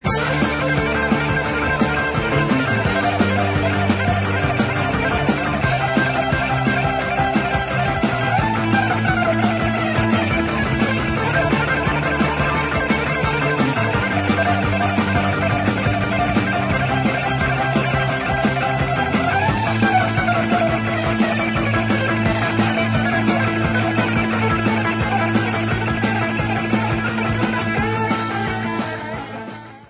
Ils sont au format mp3, 32 kbps, 22 KHz, mono.